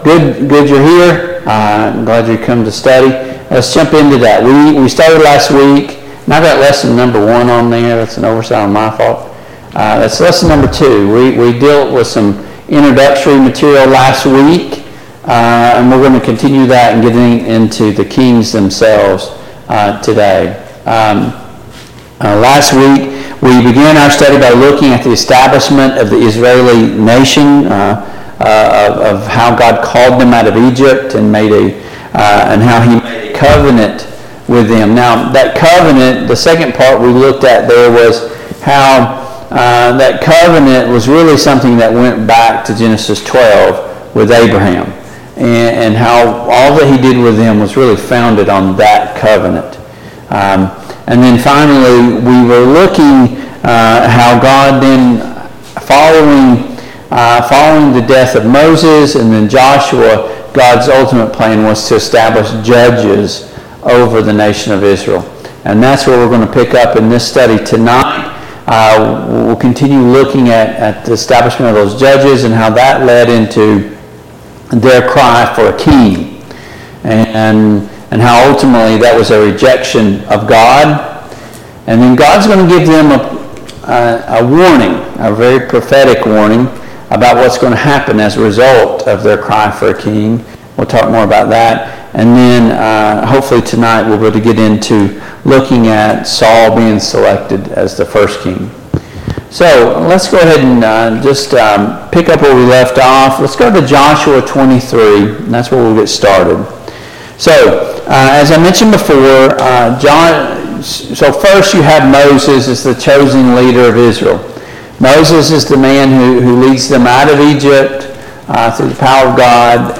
The Kings of Israel Service Type: Mid-Week Bible Study Download Files Notes Topics: Joshua , The children of Israel , The Judges « 4.